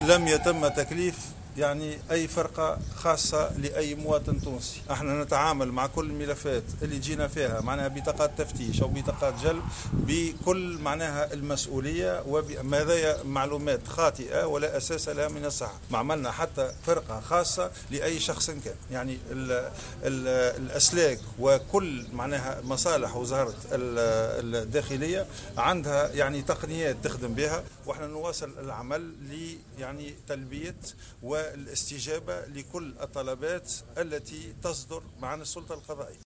indique Ghazi Jeribi, ce mardi 12 juin 2018, dans une déclaration rapportée par Mosaïque Fm.